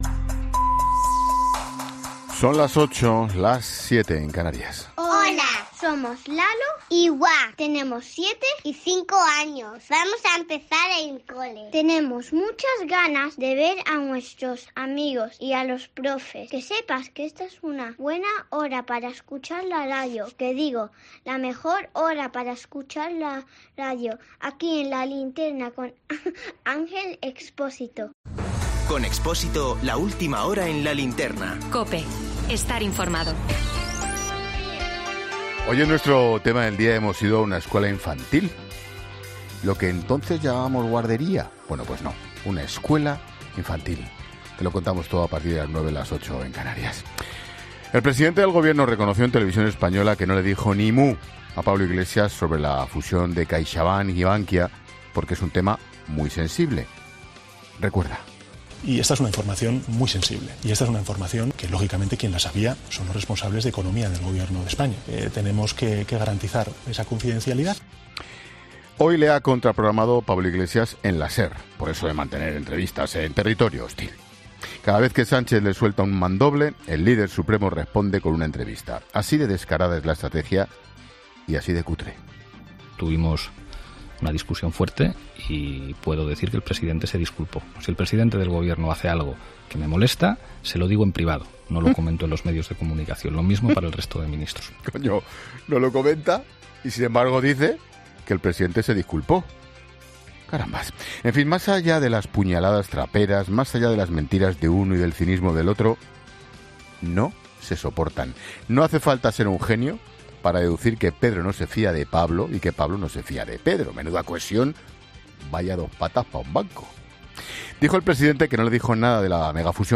Monólogo de Expósito
El director de 'La Linterna', Ángel Expósito, analiza en su monólogo la desconfianza mutua que existiría entre Pedro Sánchez y Pablo Iglesias